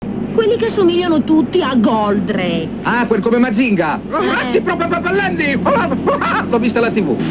Anche Banfi in una scena del film parlando del vero Mazinga, in perfetto-finto barese parla di "rezzi propellenti"
Un estratto audio del film
linobanfi_mazinga.wav